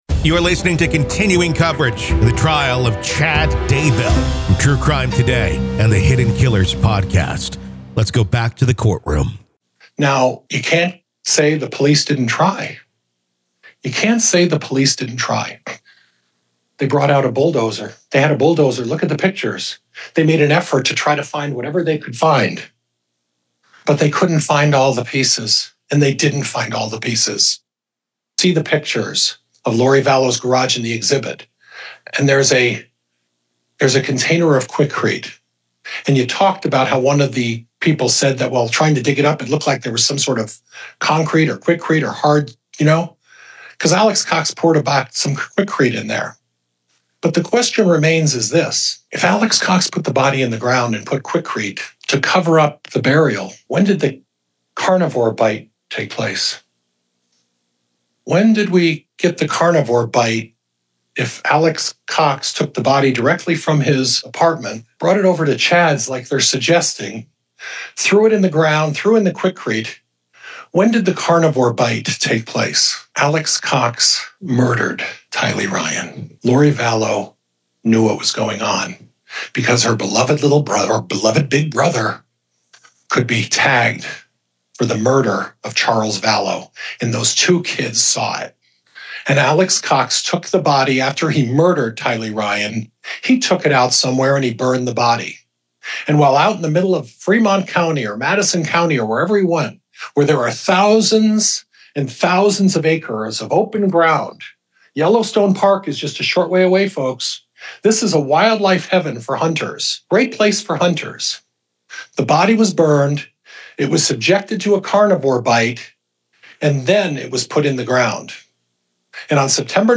Defense Closing Arguments ID v Chad Daybell, Doomsday Prophet Murder Trial PART 3